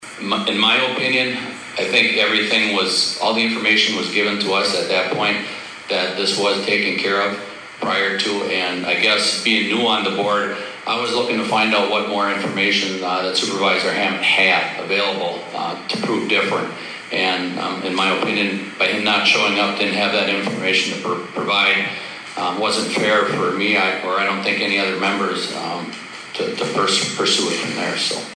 SHAWANO, WI- More fireworks erupted at the Shawano County Board meeting on Wednesday, as allegations of employee misconduct and an unsubstantiated claim about missing county funds stirred another tense debate among supervisors.